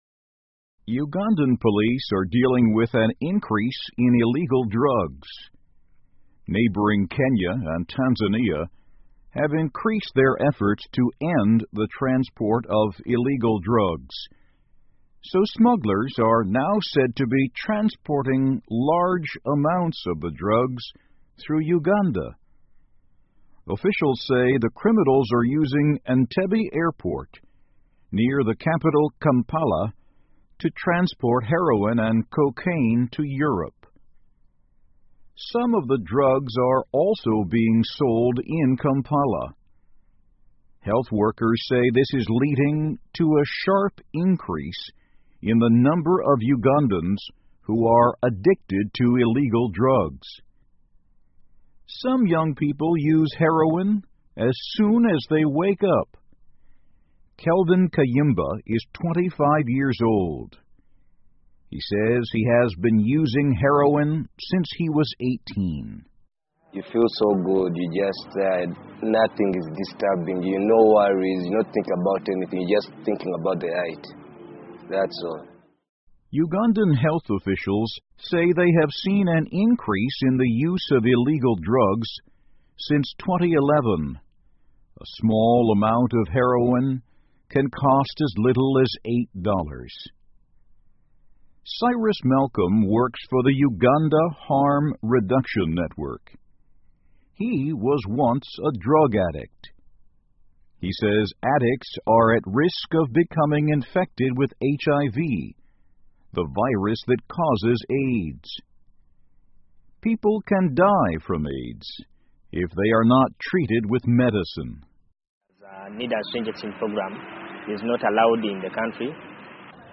在线英语听力室乌干达吸毒人员增加的听力文件下载,2015年慢速英语(十)月-在线英语听力室